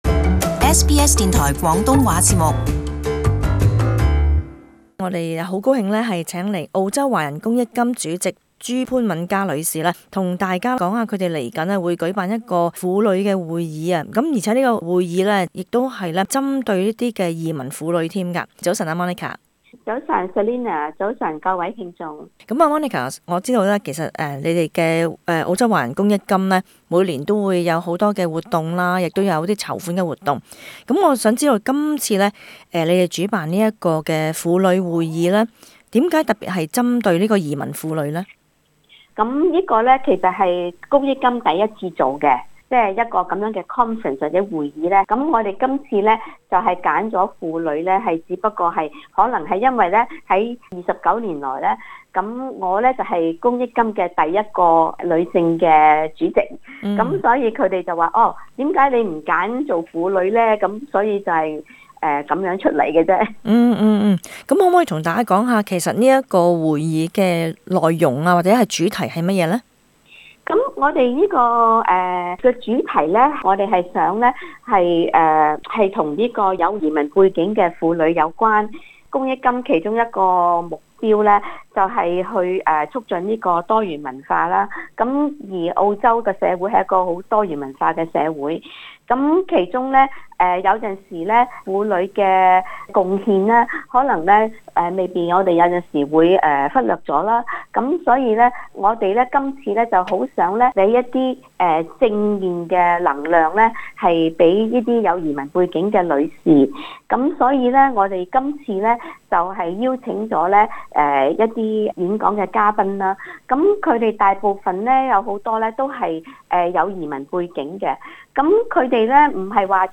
【雪梨社區專訪】澳華公益金舉辦首個移民婦女會議